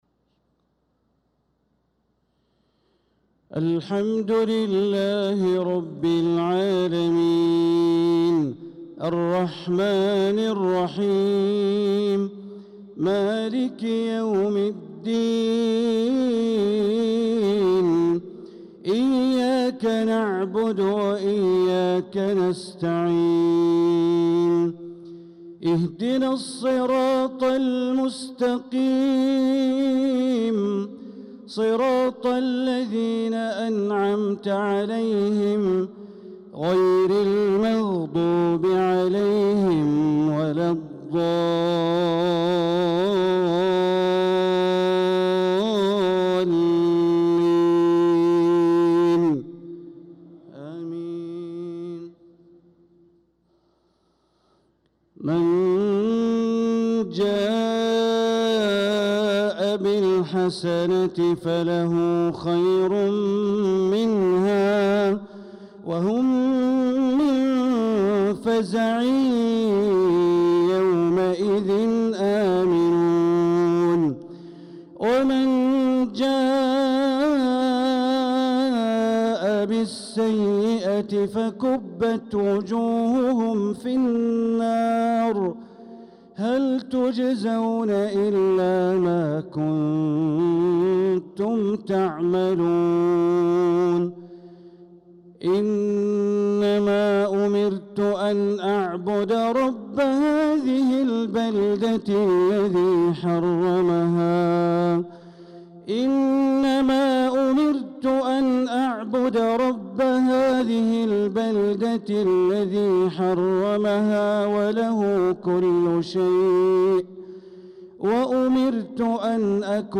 صلاة العشاء للقارئ بندر بليلة 9 ذو الحجة 1445 هـ
تِلَاوَات الْحَرَمَيْن .